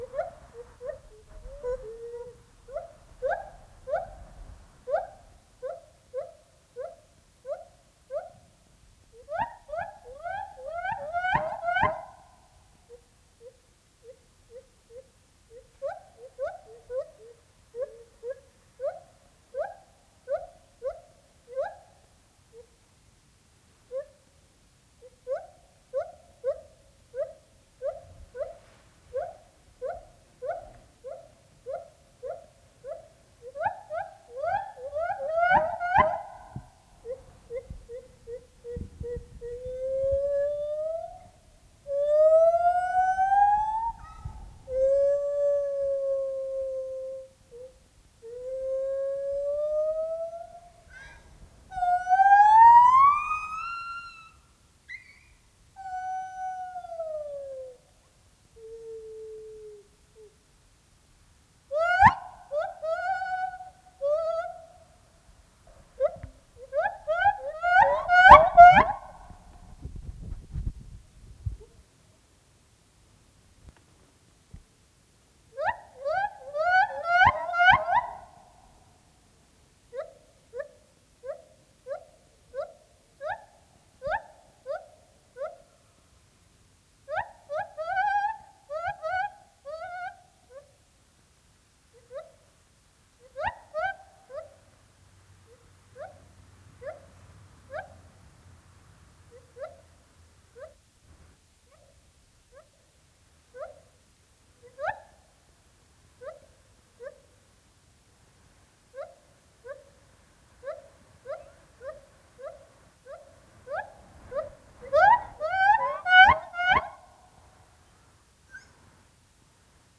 Directory Listing of /_MP3/allathangok/nyiregyhazizoo2010_standardt/feherkezugibbon/
szelvedovelkeszultfelvetel03.41.wav